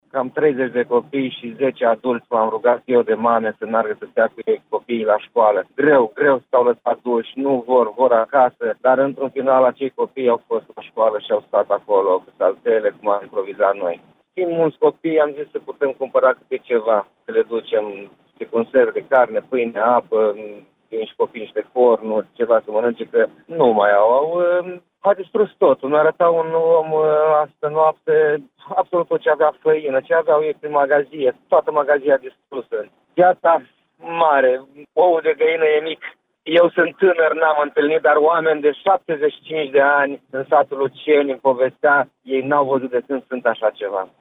Primarul comunei ieșene Victoria, Daniel Crețu, a mai declarat pentru postul nostru de radio că 30 de copii și 10 adulți din satul Icușeni au fost evacuați din locuințe și cazați noaptea trecută la școala din localitate.